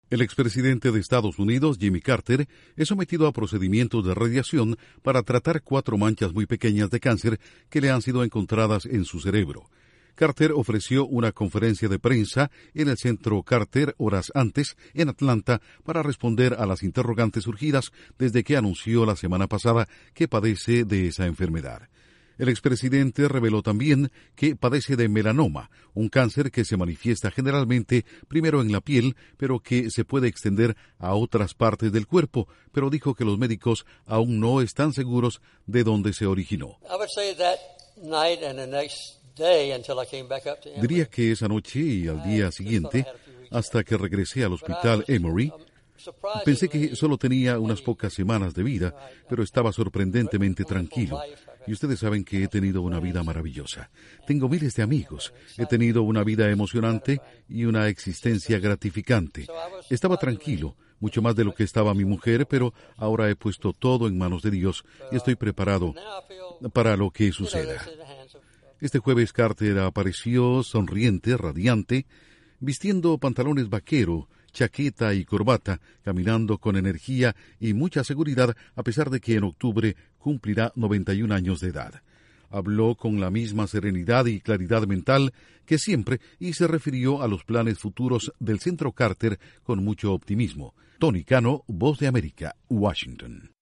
El ex presidente de Estados Unidos, Jimmy Carter, tiene cáncer en el cerebro y es sometido a procedimientos de radiación. Informa desde la Voz de América en Washington